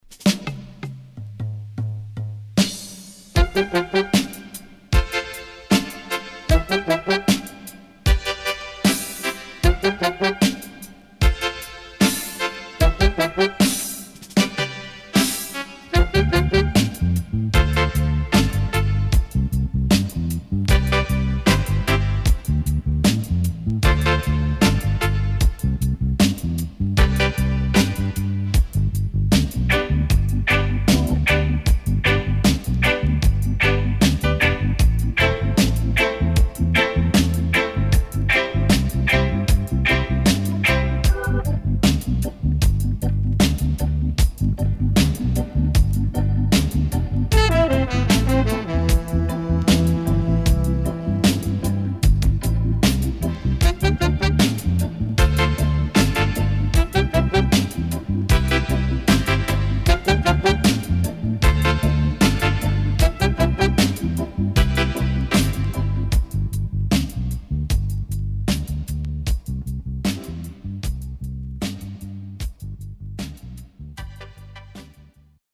CONDITION SIDE A:VG+〜EX-
Nice Vocal
SIDE A:少しノイズ入りますが良好です。